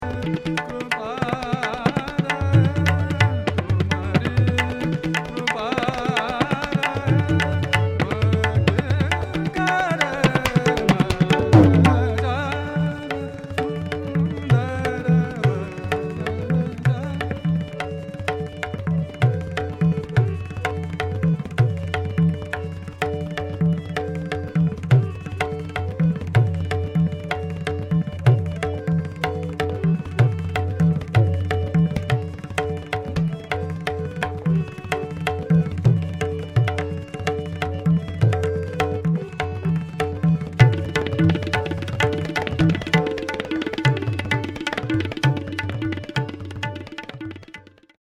recitation, tabla
western drums
vocal
sitar , harmonium